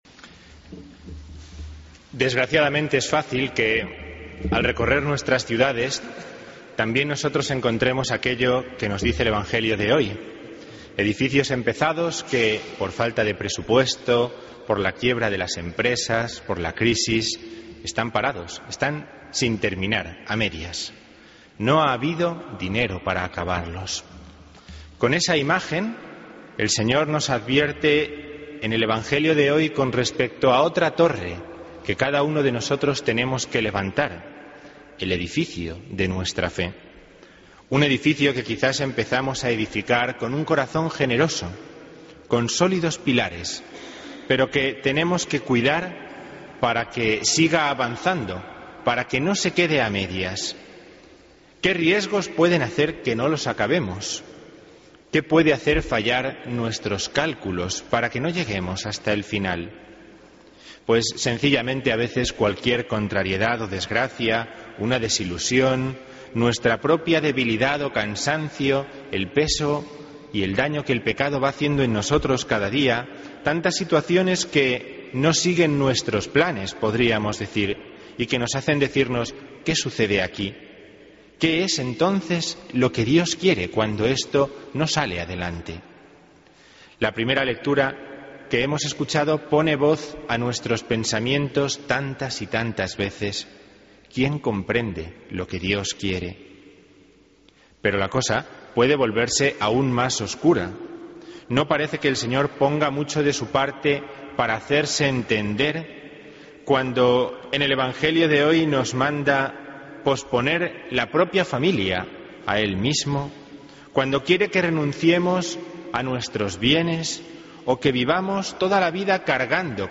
Homilía 8 de Septiembre 2013